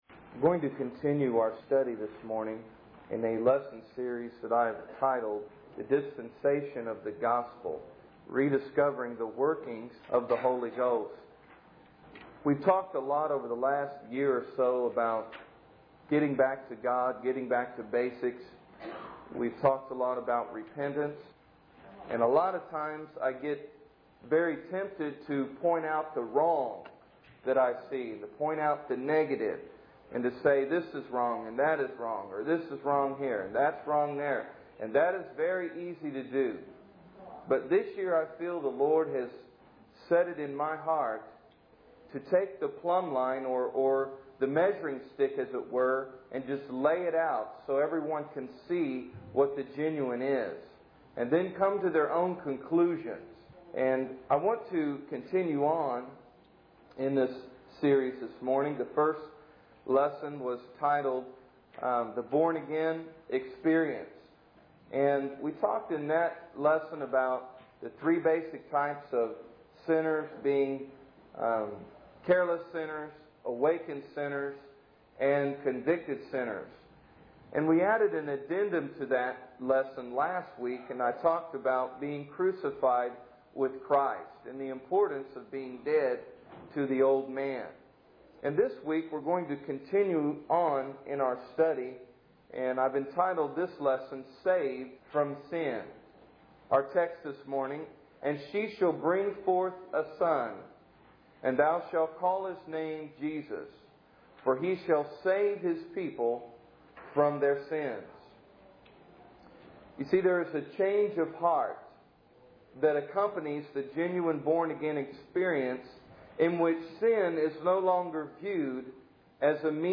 In this sermon, the preacher emphasizes the importance of understanding biblical principles in order to effectively win souls for God. He explains that man is responsible for knowing and following God's will, as they are born with the knowledge of right and wrong through the law of God written on their hearts.